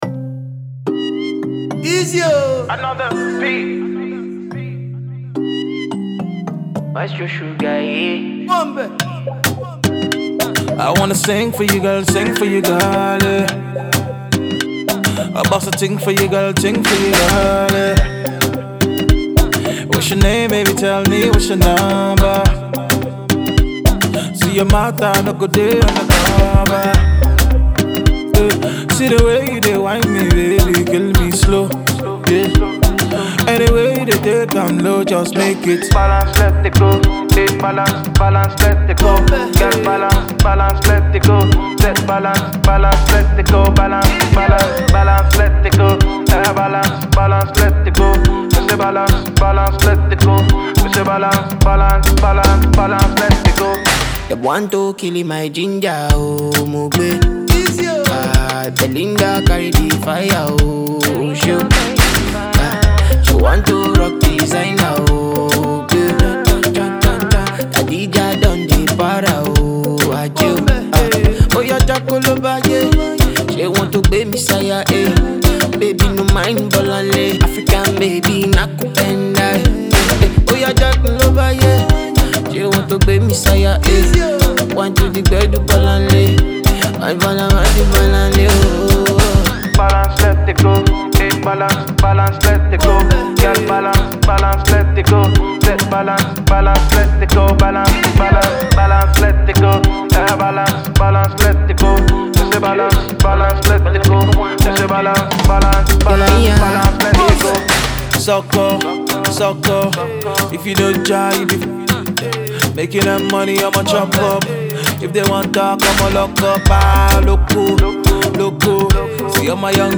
South London Afrobeats artist and producer